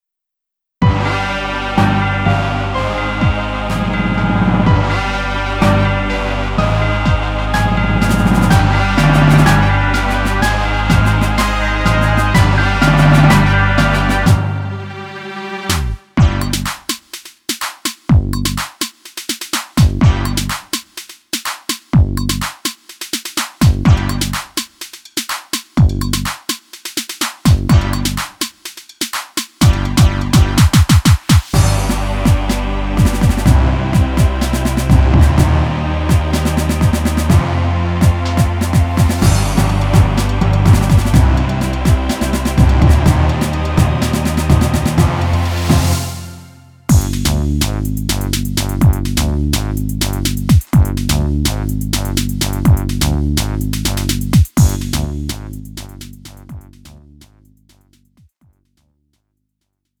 음정 원키 2:55
장르 가요 구분 Lite MR